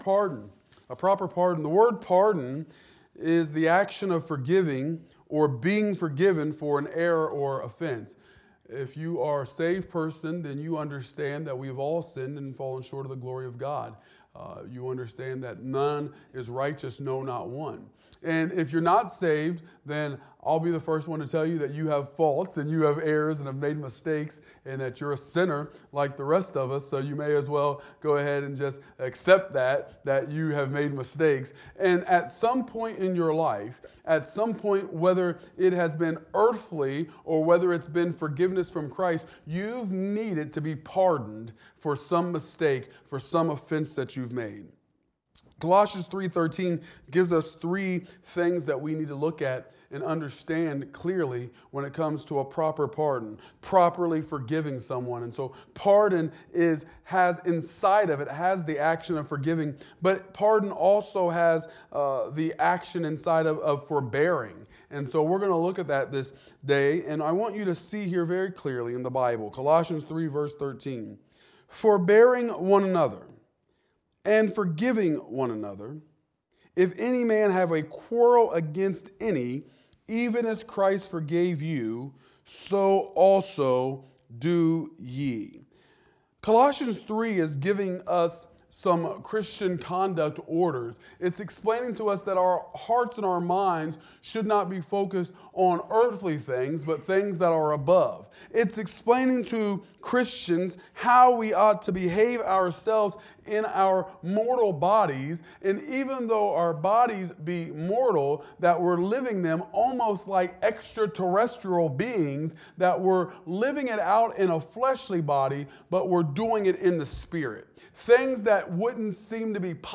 Wednesday Bible Study